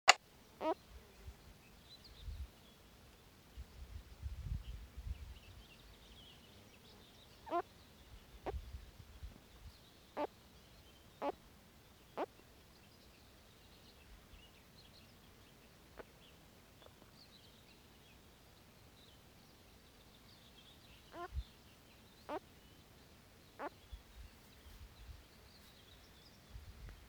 Amphibians -> Spadefoot toads -> 1
Common spadefoot (Garlic toad), Pelobates fuscus
Notes Rokot dārzā zemi iztraucēts,izdod skaņas, būdams ieracies.